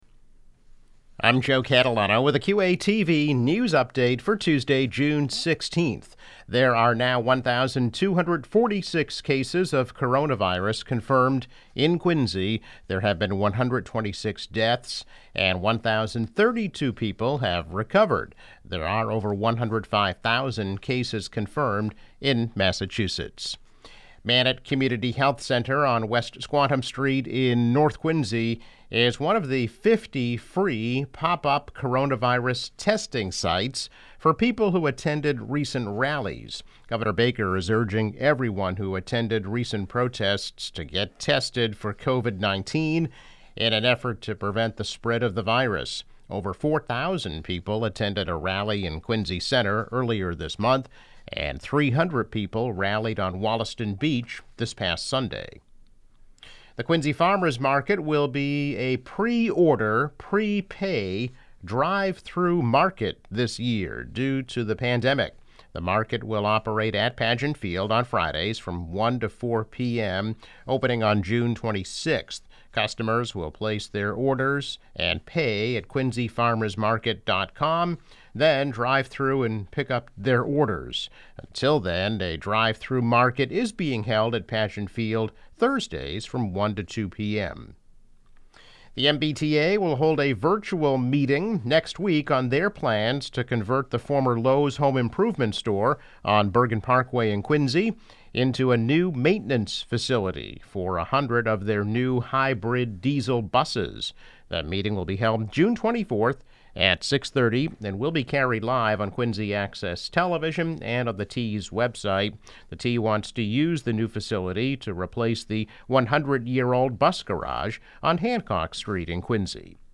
Daily news update.